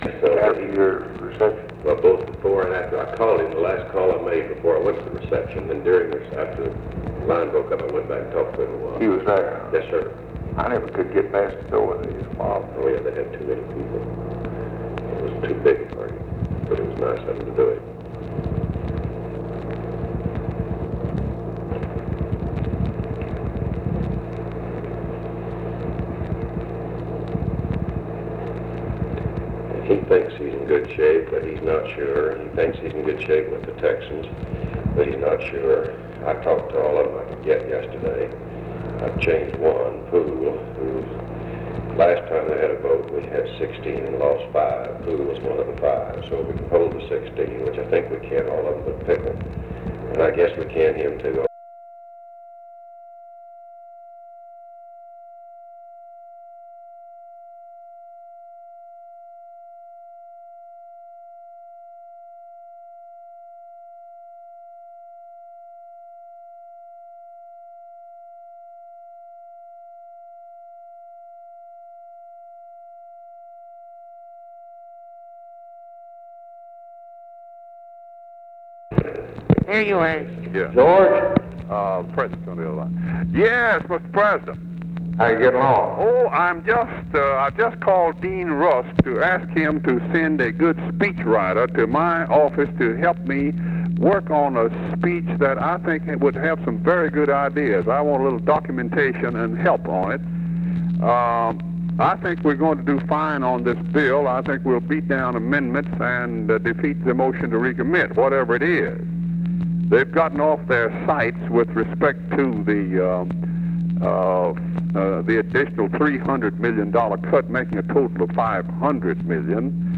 Conversation with GEORGE MAHON and OFFICE CONVERSATION, June 30, 1964
Secret White House Tapes